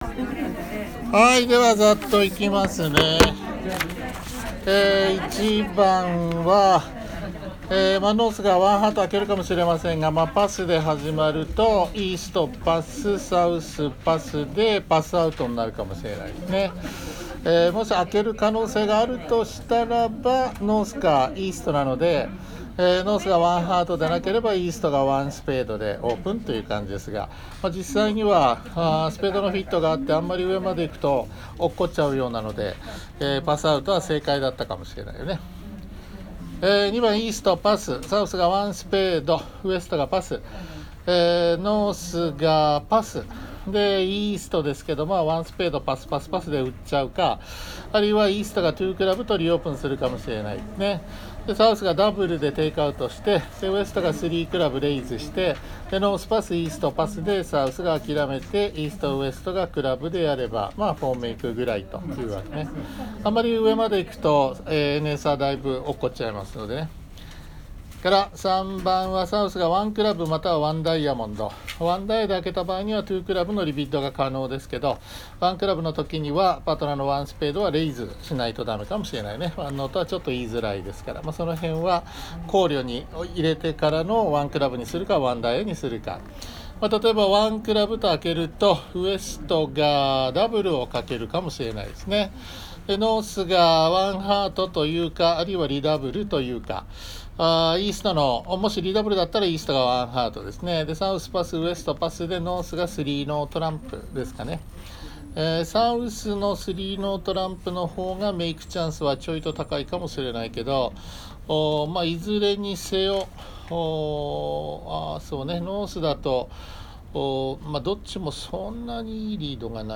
ハンド音声解説前半